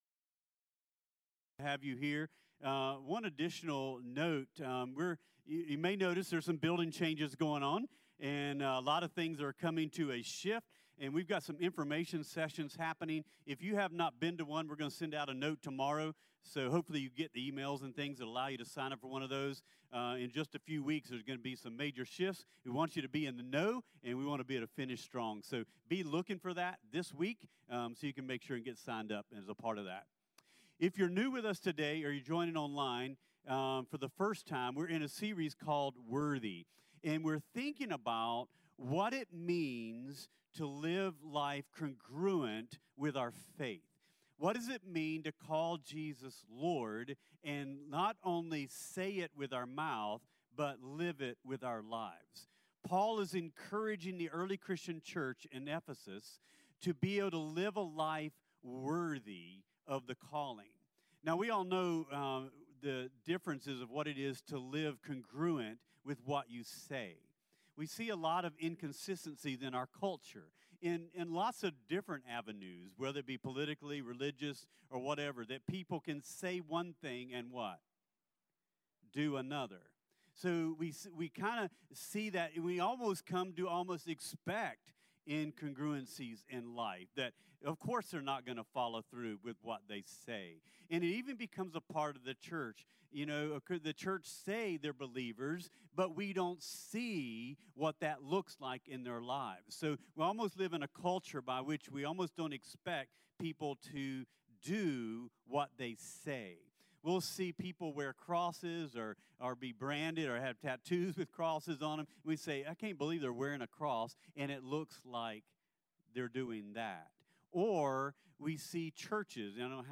CTK-October-23-Clipped-Sermon-1.mp3